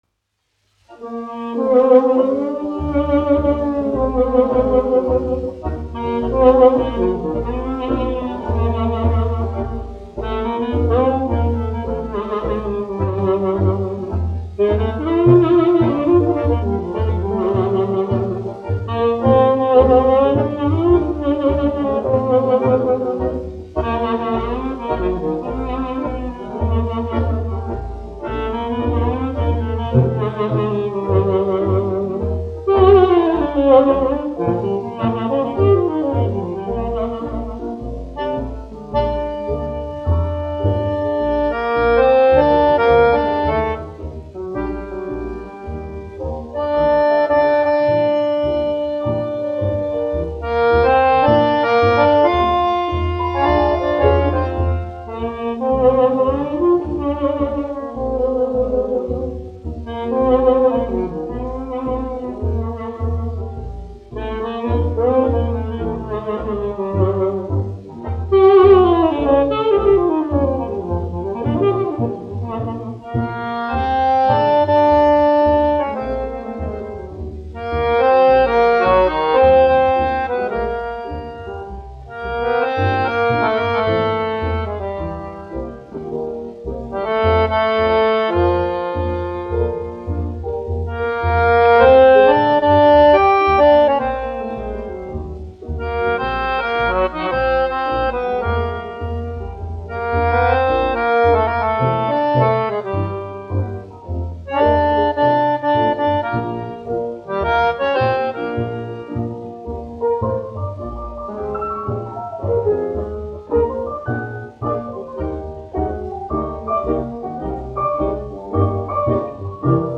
1 skpl. : analogs, 78 apgr/min, mono ; 25 cm
Populārā instrumentālā mūzika
Fokstroti
Latvijas vēsturiskie šellaka skaņuplašu ieraksti (Kolekcija)